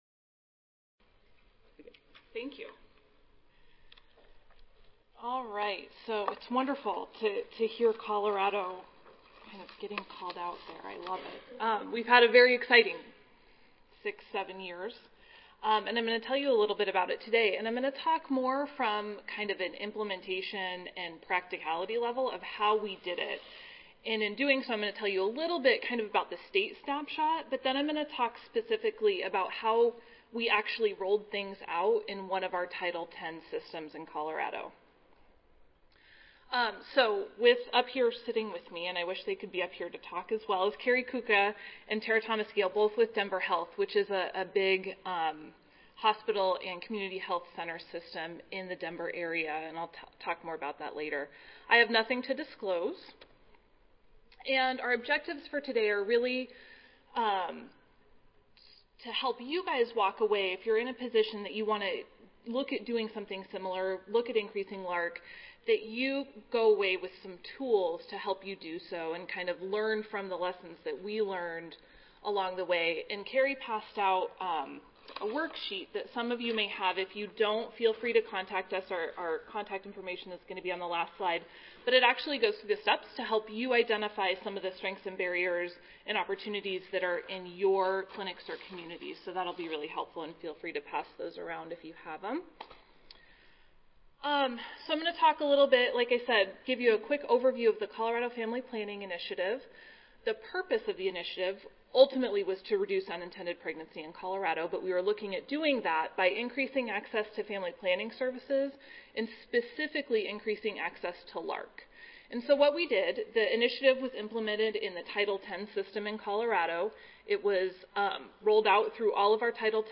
142nd APHA Annual Meeting and Exposition (November 15 - November 19, 2014): Long acting reversible contraceptive methods
recording Recorded Presentation